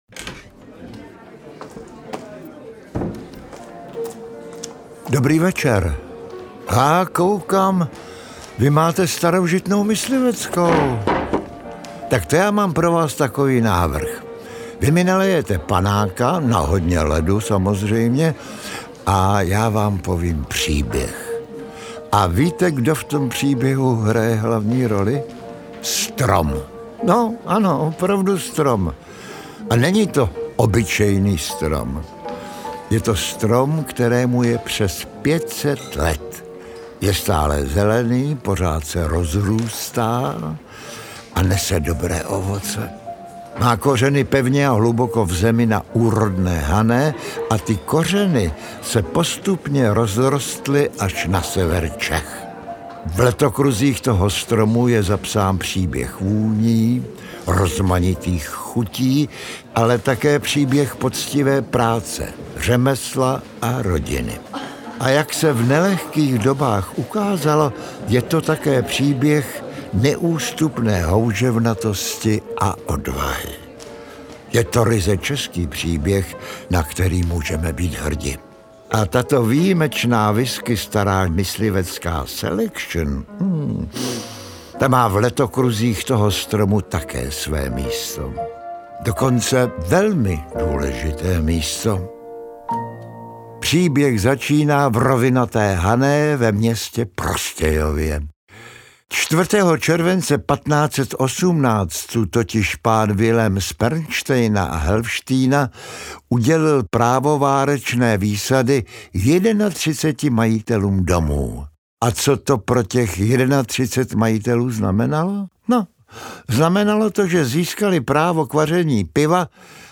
Od-korenu-po-korunu-–-audiokniha-od-Palirny-U-Zeleneho-stromu.mp3